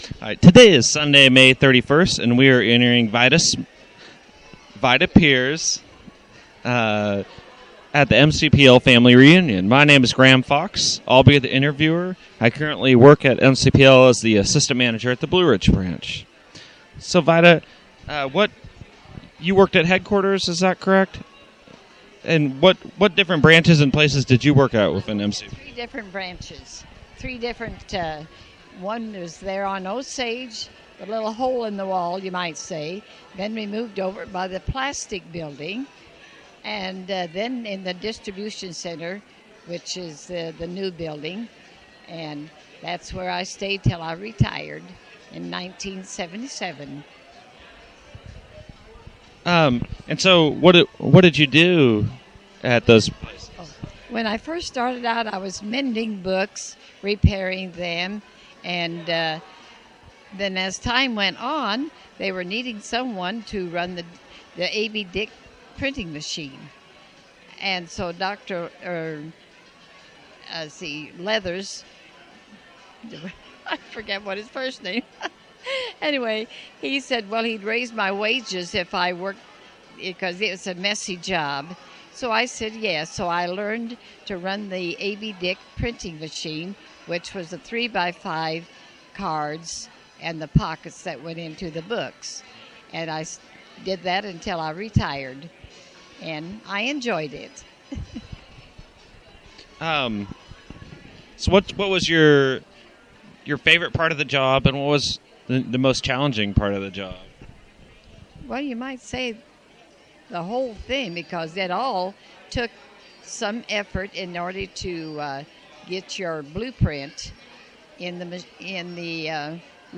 interviewer
interviewee